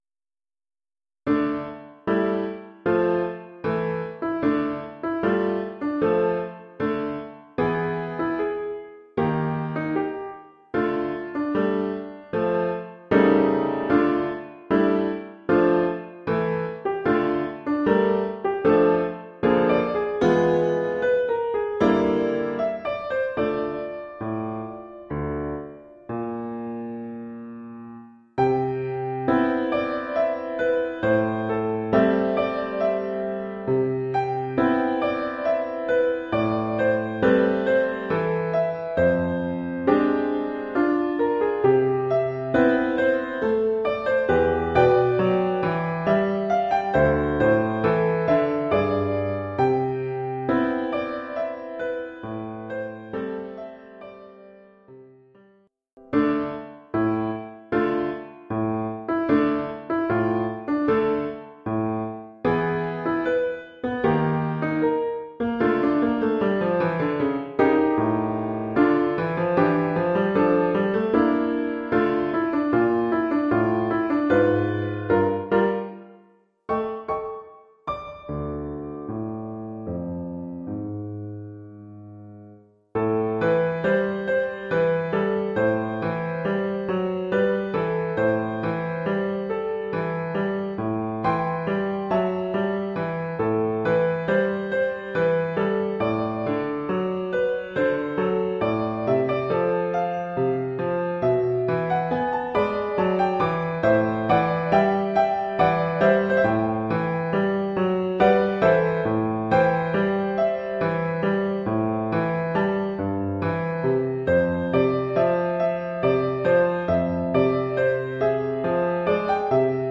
Cinq pièces pour piano.